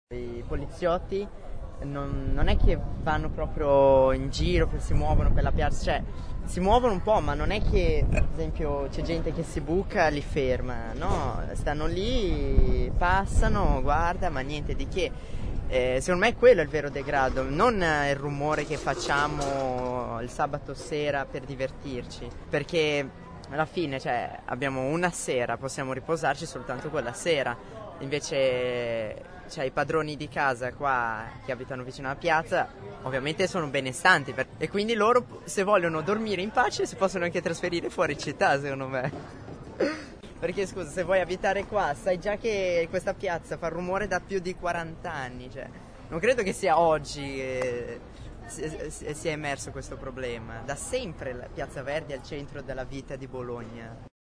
Da un giro in piazza, registratore alla mano, e due chiacchiere con chi la piazza la vive ma anche con chi in piazza ci vive, sono emersi i diversi punti di vista che alimentano il dibattito.
Uno studente